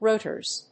/ˈrutɝz(米国英語), ˈru:tɜ:z(英国英語)/